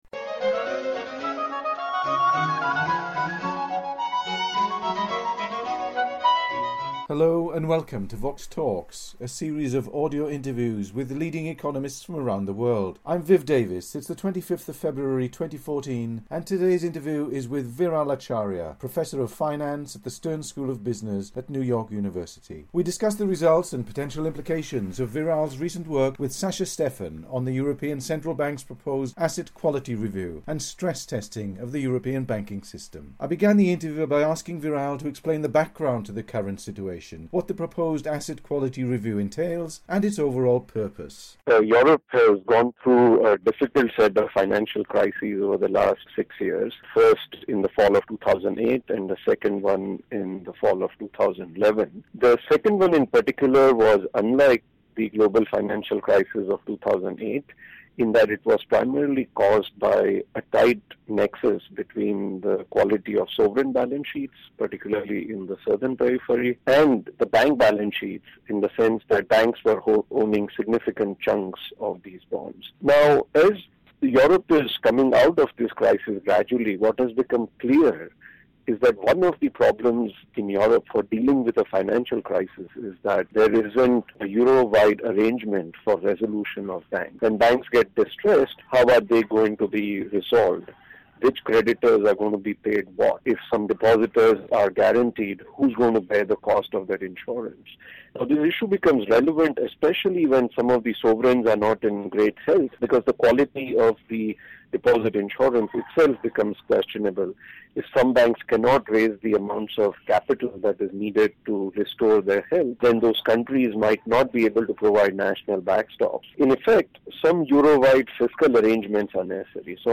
They also discuss the difference in accounting rules between US and EZ banks and the future potential for banking union in the Eurozone. The interview was recorded by phone on 25 February 2014.